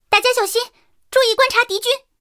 三号夜战语音.OGG